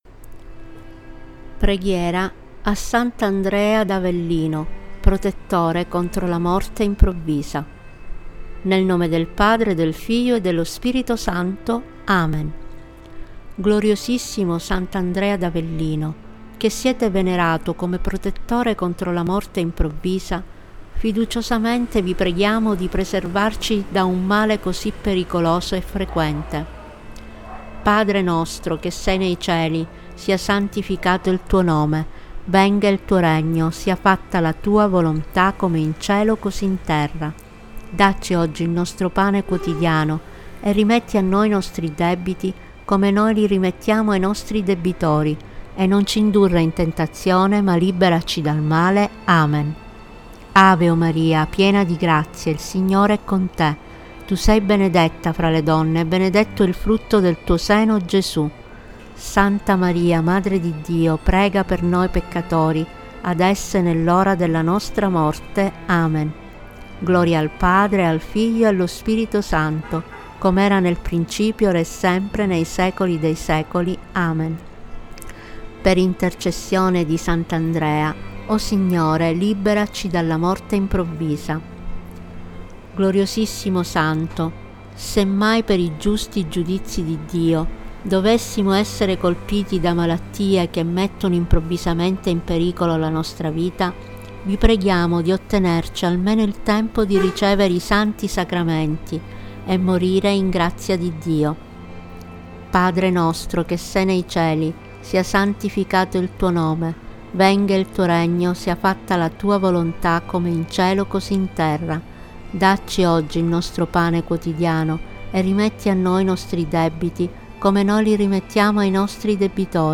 Preghiera a S. Andrea Avellino, protettore contro la morte improvvisa, con voce guida mp3.
Preghiera-contro-morte-improvvisa-audio-e-musica-Tempo-di-preghiera.mp3